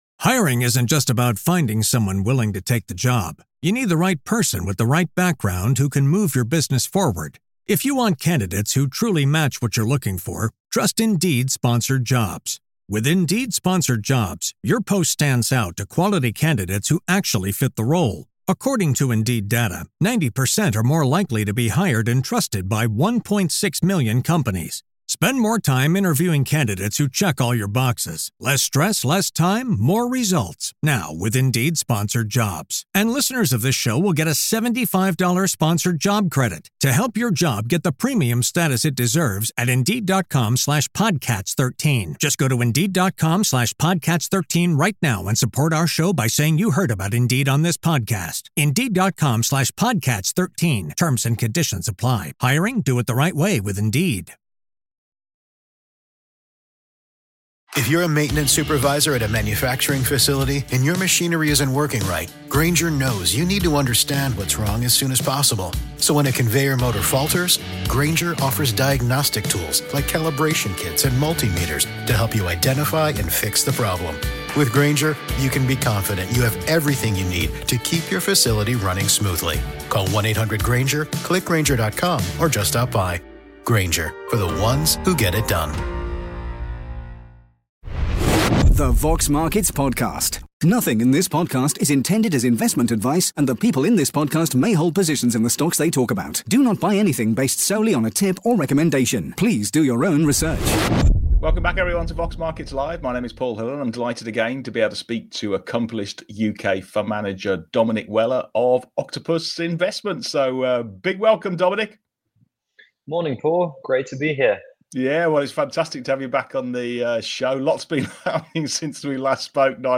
In this week’s live Exchange